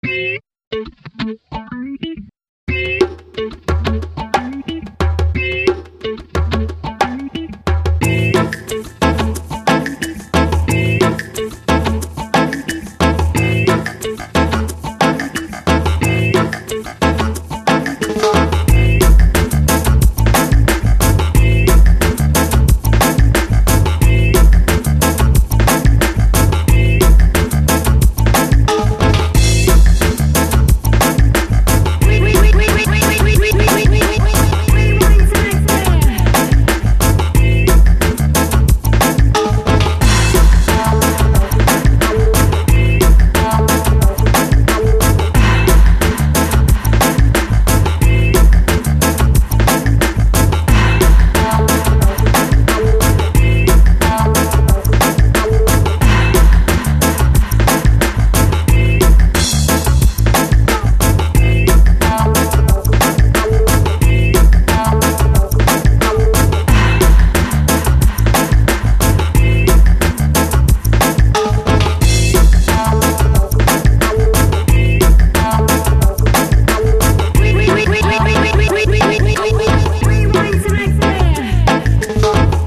son home studio